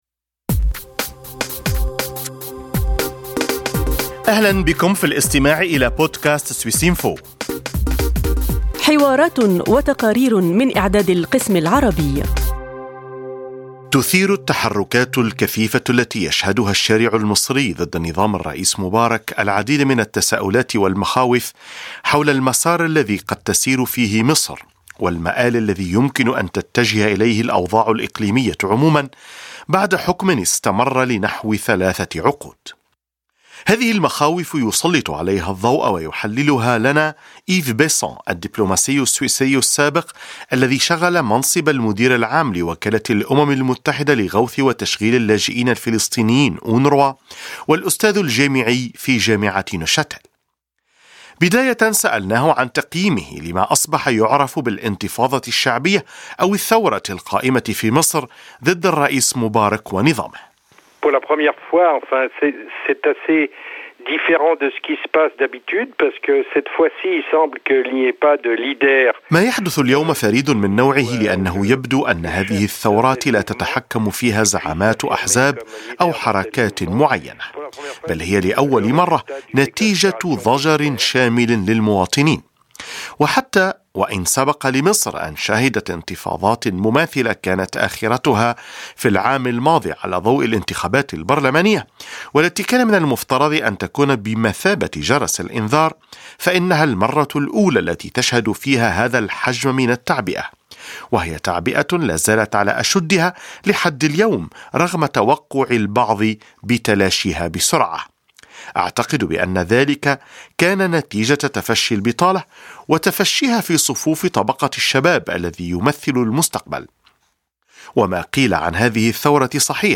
دبلوماسي سويسري سابق يدعو الدول الأوروبية إلى تغيير أسلوب تعاطيها مع الأوضاع السياسية في بلدان الشرق الأوسط قبل فوات الأوان.